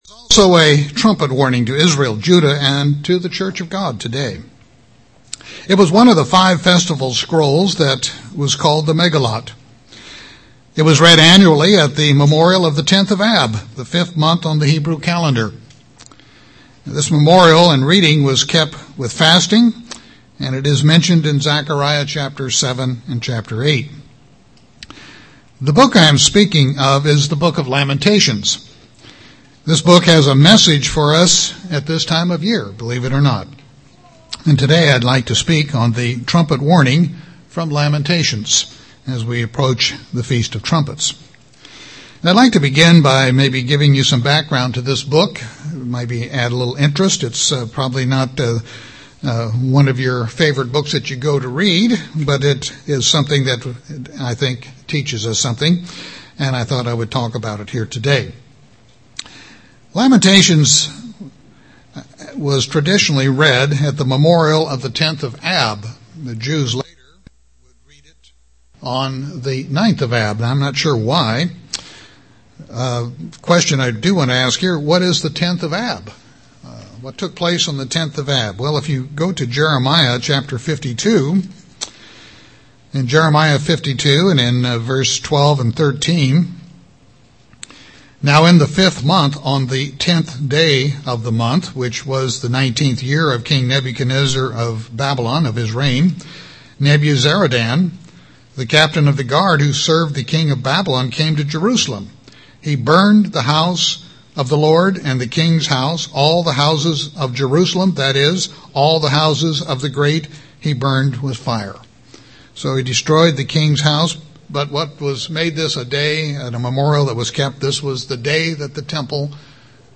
9-24-11 Serrmon.mp3